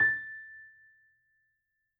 piano_081.wav